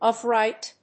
アクセントof ríght